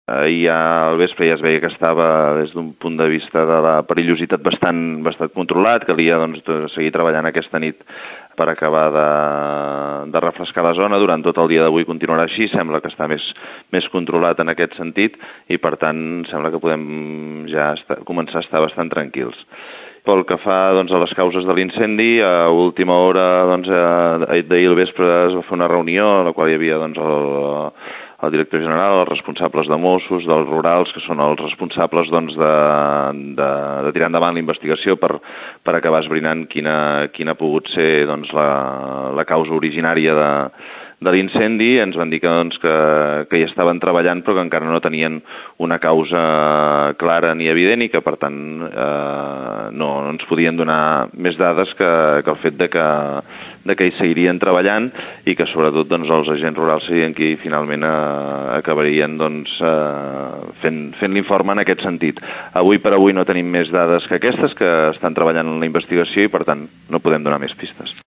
Escoltem Josep Llorens, primer tinent d’Alcalde del nostre municipi.